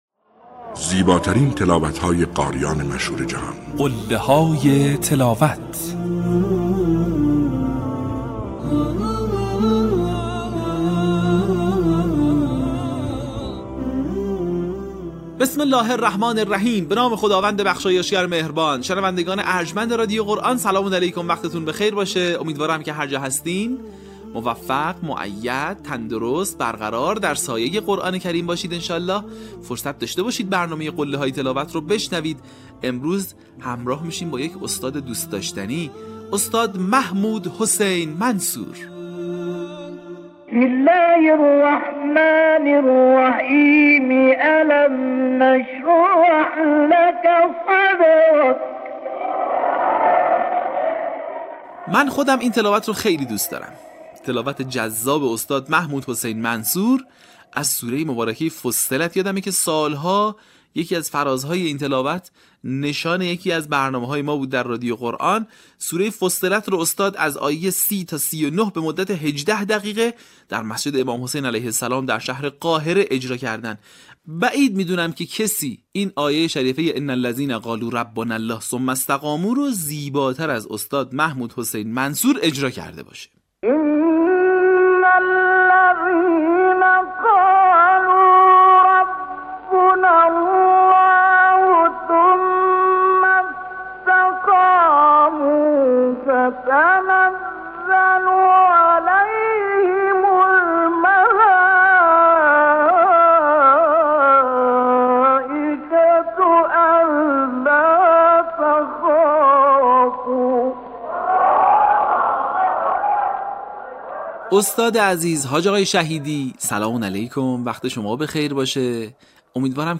در قسمت چهل‌وسه فراز‌های شنیدنی از تلاوت‌های به‌یاد ماندنی استاد محمود حسین منصور را می‌شنوید.
برچسب ها: محمود حسین منصور ، قله های تلاوت ، تلاوت ماندگار ، تلاوت تقلیدی